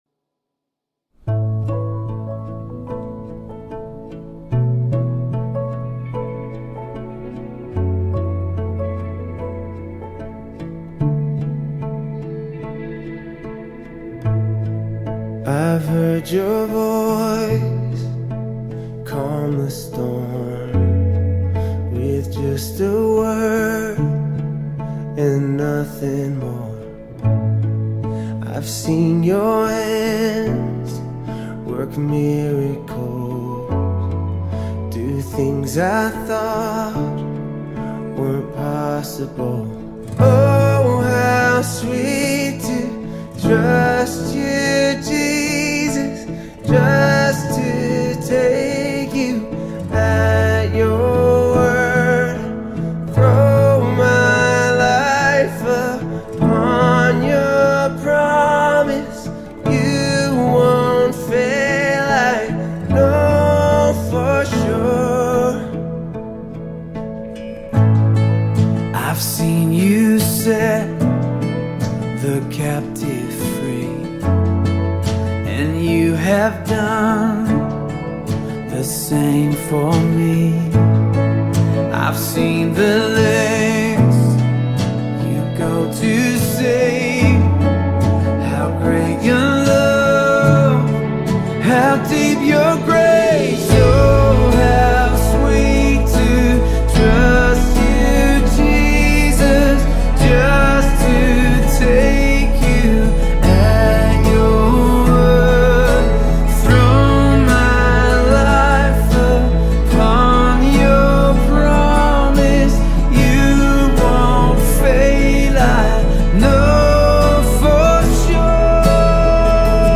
Mp3 Gospel Songs
harmonious vocals add depth and warmth to the song